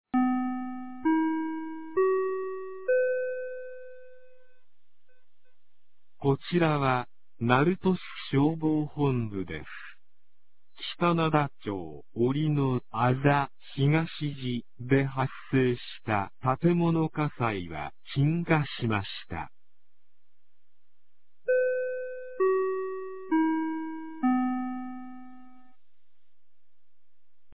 2025年09月07日 07時21分に、鳴門市より北灘町-粟田、北灘町-大浦、北灘町-折野、北灘町-櫛木へ放送がありました。